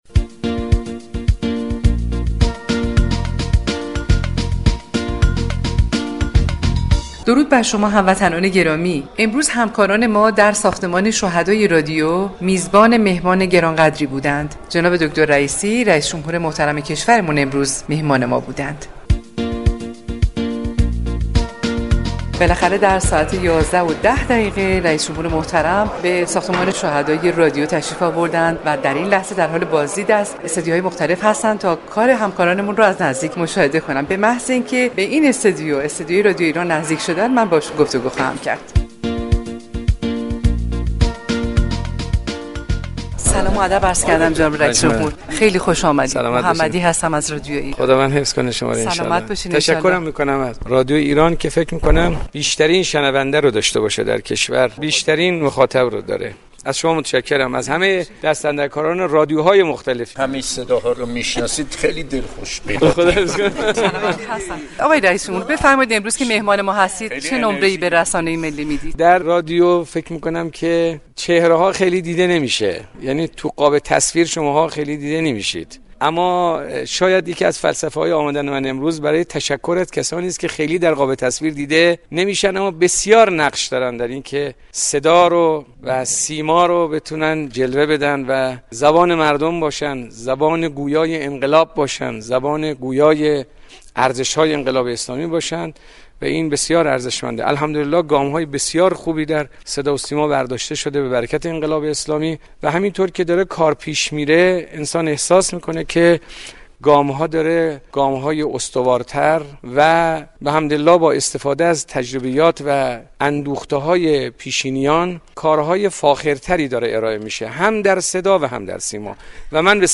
حجت الاسلام رییسی پس از ورود به ساختمان شهدای رادیو و بازدید از استودیو یك رادیو ، سرزده به یكی از استودیوهای تولید برنامه های رادیو رفت و از نزدیك در جریان روند كار برنامه سازان قرار گرفت.